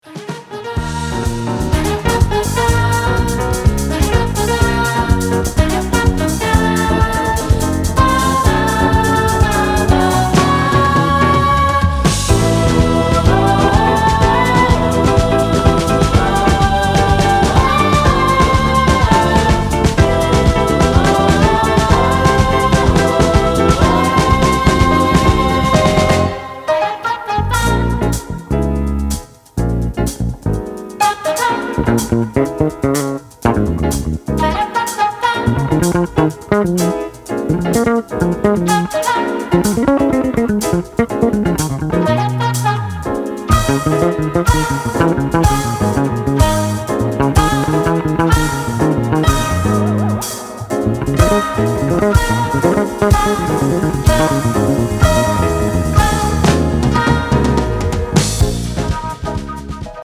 ジャンル(スタイル) JAZZY HOUSE / CROSSOVER